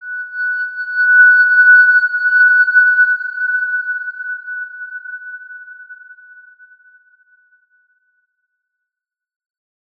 X_Windwistle-F#5-ff.wav